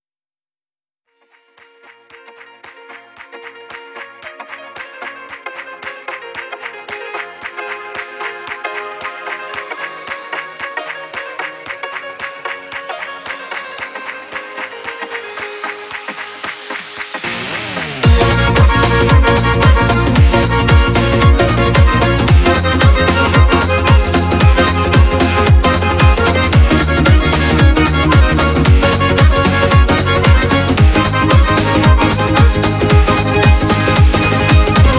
Жанр: Музыка мира / Русские